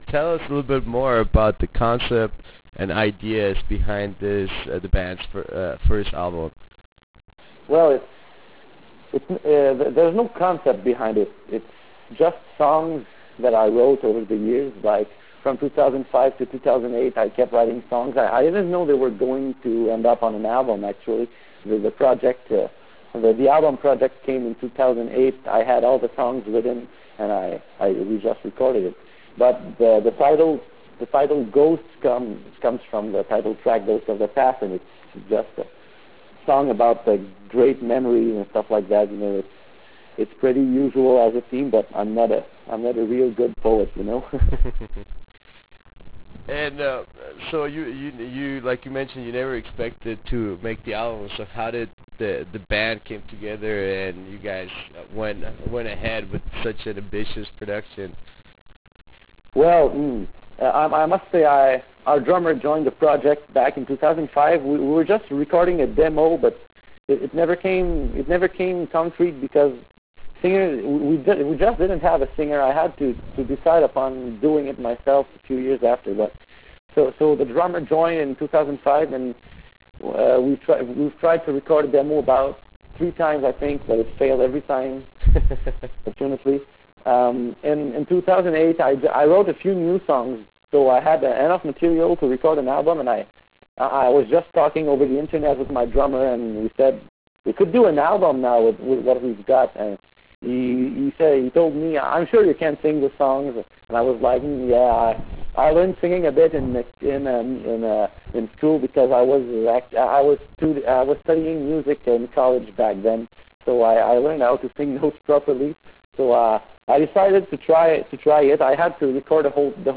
Interview with Instanzia
Interview with Instanzia.wav